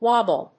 音節wab・ble 発音記号・読み方
/wάbl(米国英語), wˈɔbl(英国英語)/